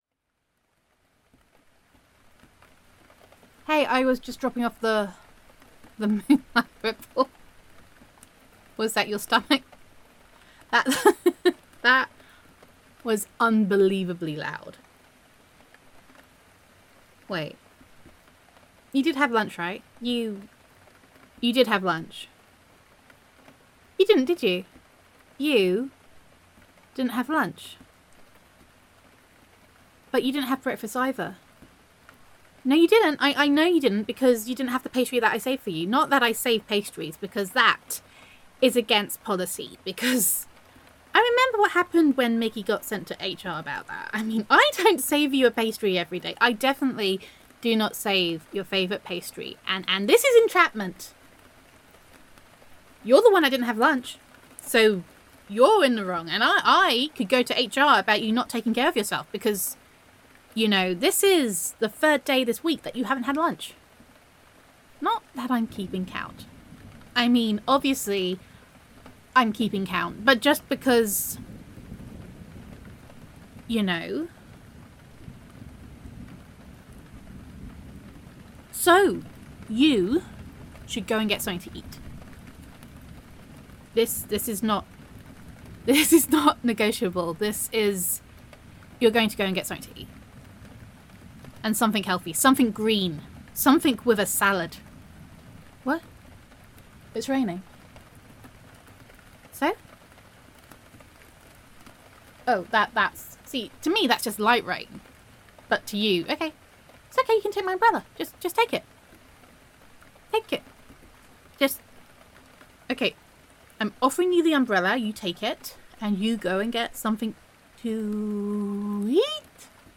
[F4A] Hidden in Rain Sight [Co-Workers to Lovers][Love Confession]